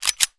gun_pistol_cock_03.wav